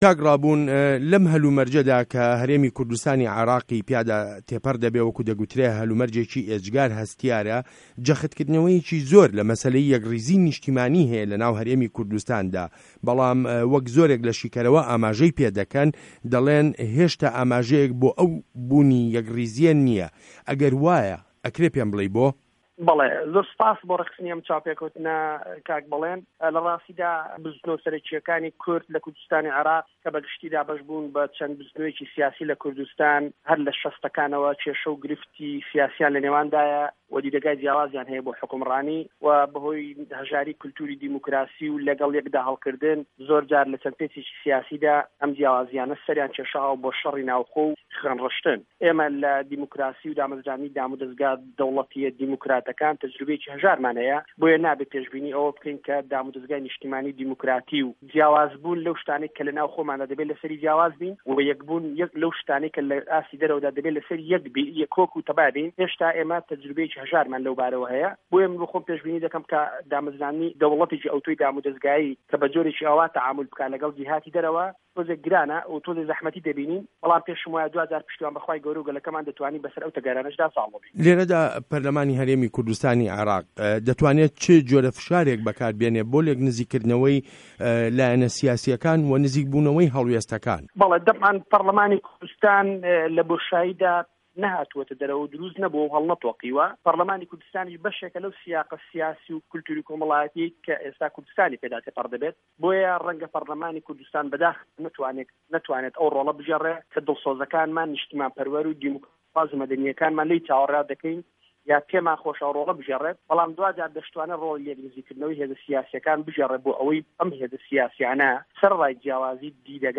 وتووێژ له‌گه‌ڵ ڕابوون مه‌عروف